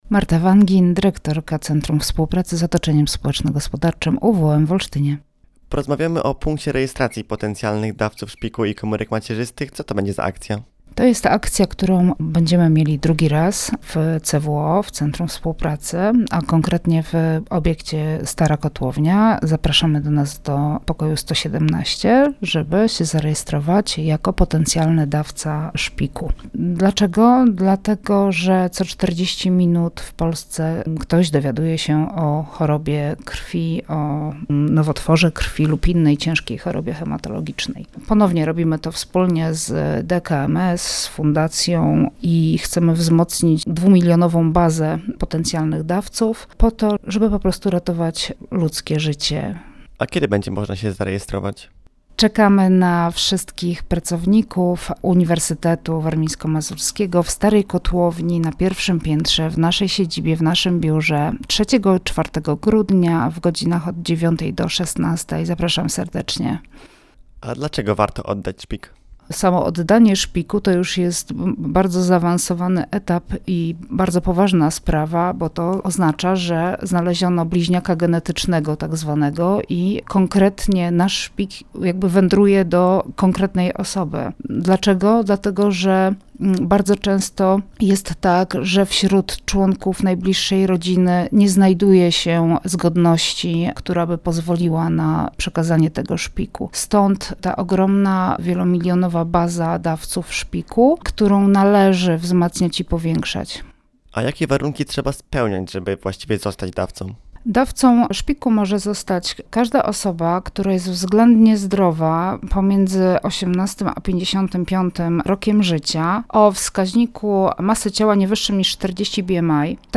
rozmawiał